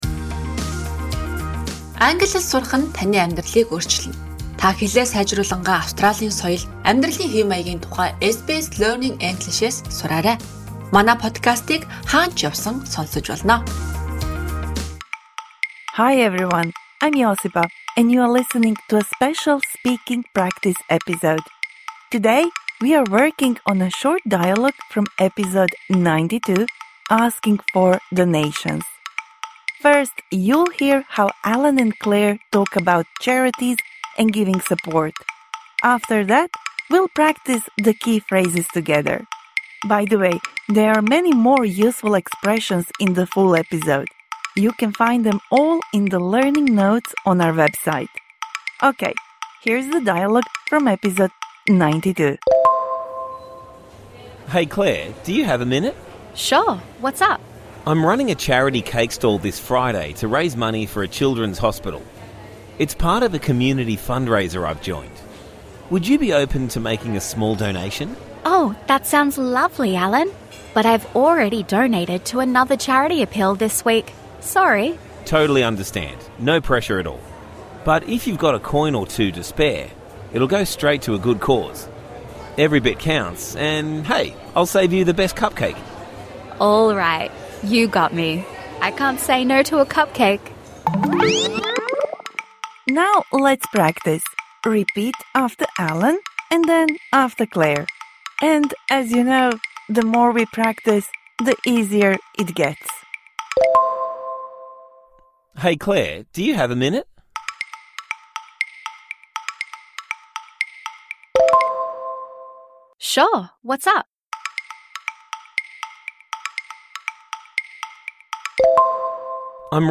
This bonus episode provides interactive speaking practice for the words and phrases you learnt in #92 Asking for donations (Med).